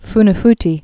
(fnə-ftē)